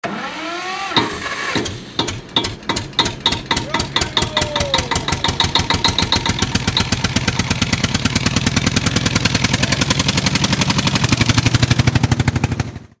starting-tractor_24975.mp3